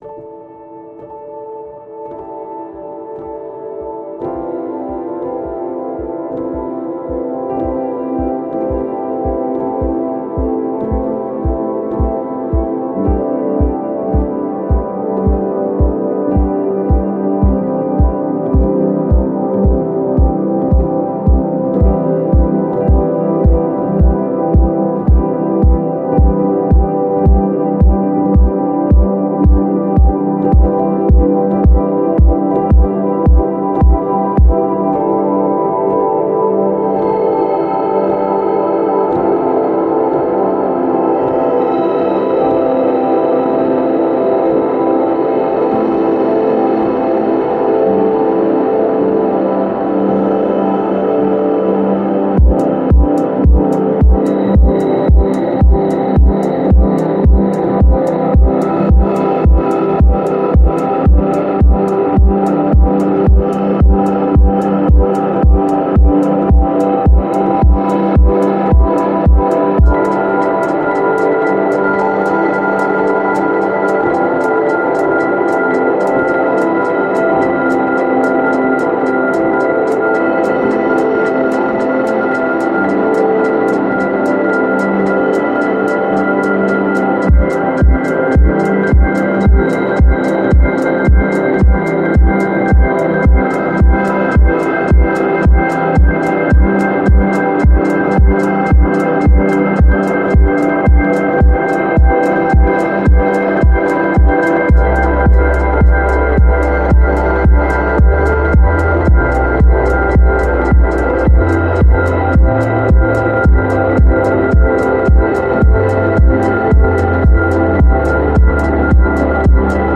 BGM 明るい